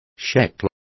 Complete with pronunciation of the translation of shekels.